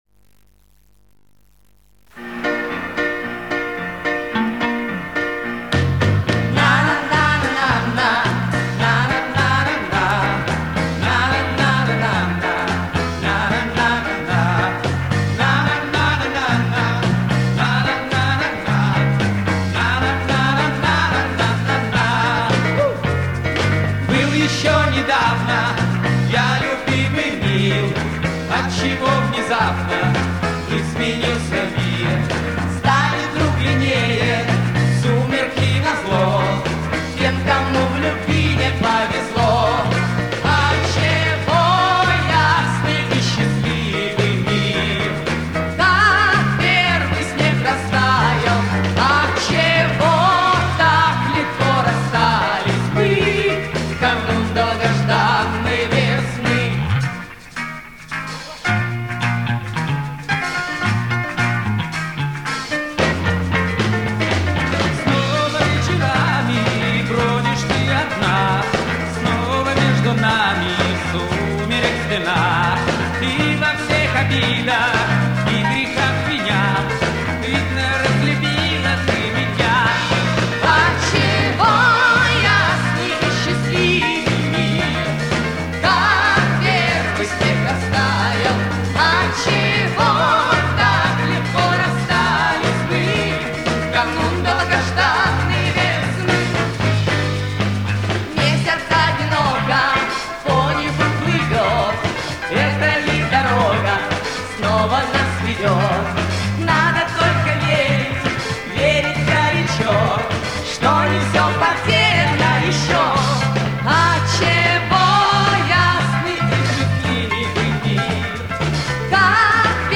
с гала-концерта 1976 года